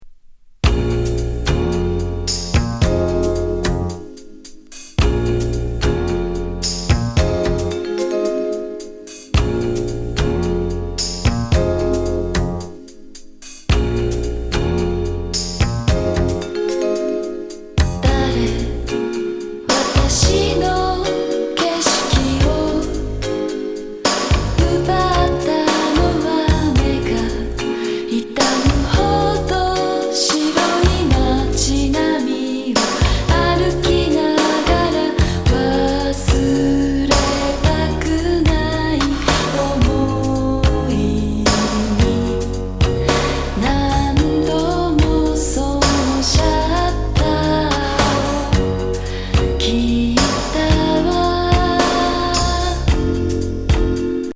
極上バレアリック・シンセ・メロウ